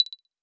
GenericNotification3.wav